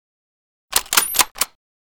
bolt.ogg